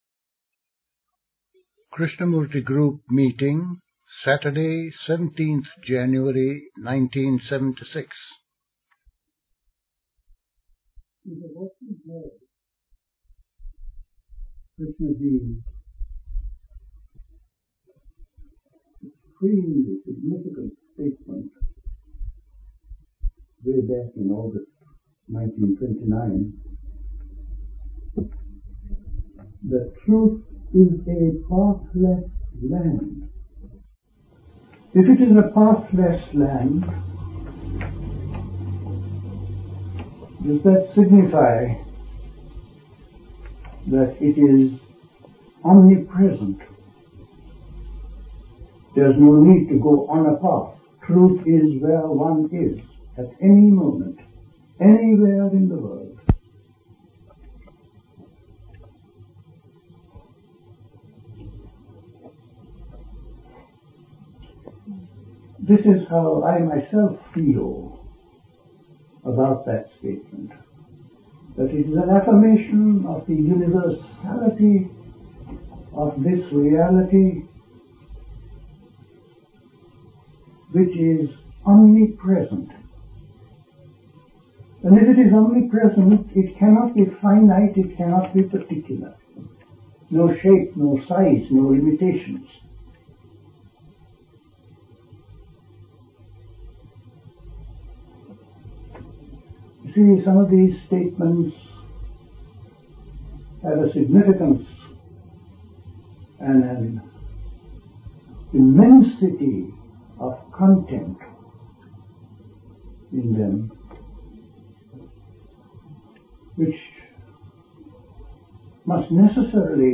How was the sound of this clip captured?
Recorded at a Krishnamurti meeting.